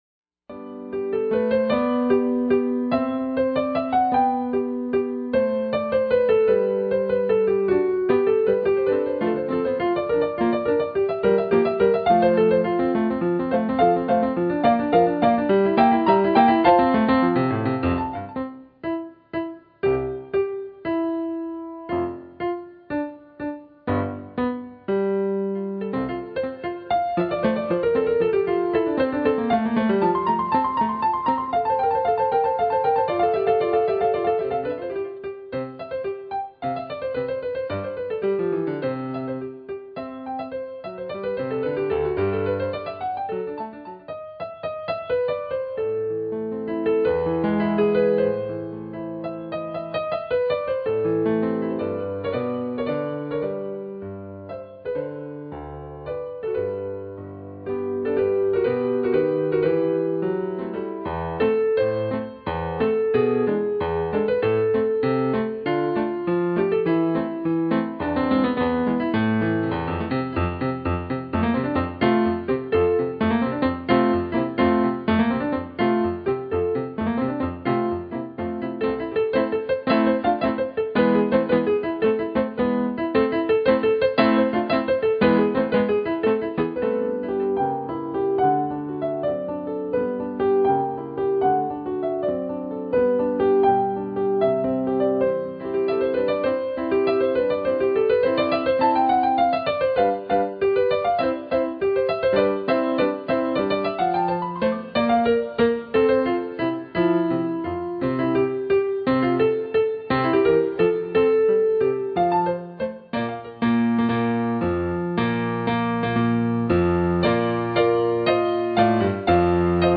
Children are fascinated by piano music!
keyboard instruments